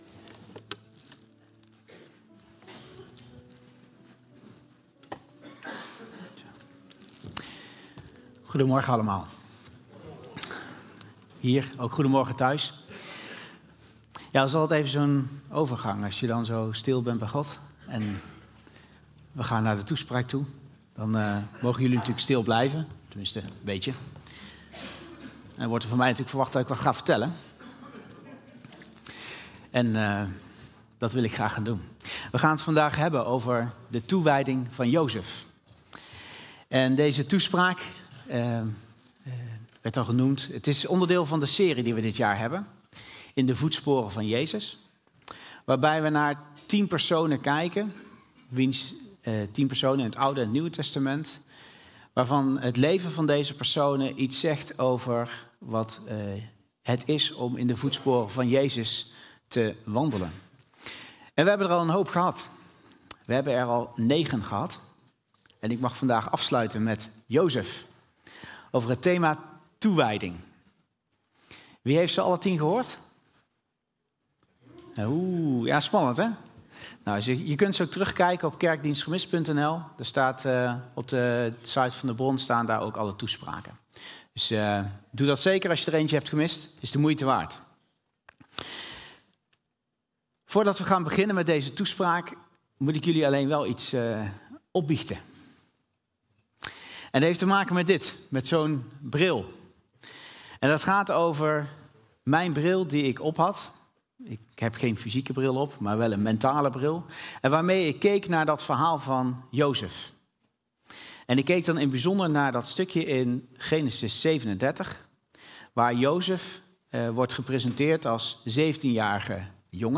Toespraak 18 mei: de toewijding van Jozef.